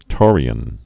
(tôrē-ən)